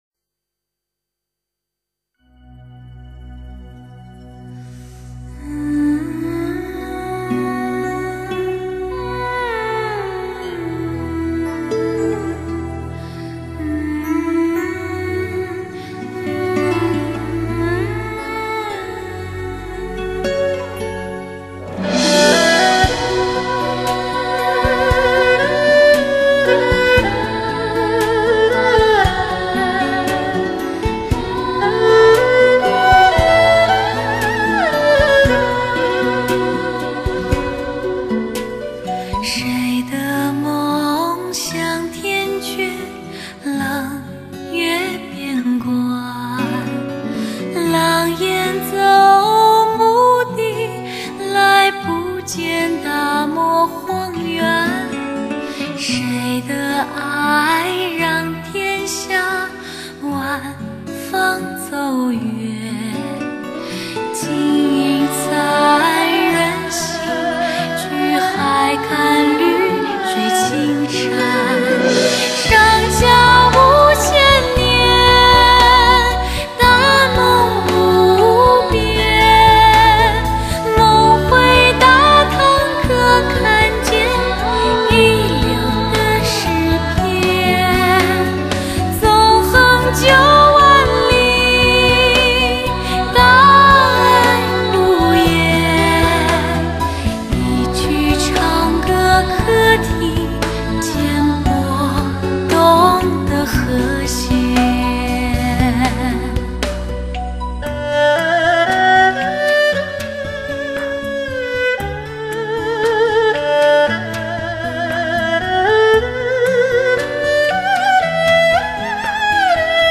民谣与流行全新演绎 年度最柔美女声
伴奏乐器如古筝、二胡、吉他、大提琴音质清晰饱满。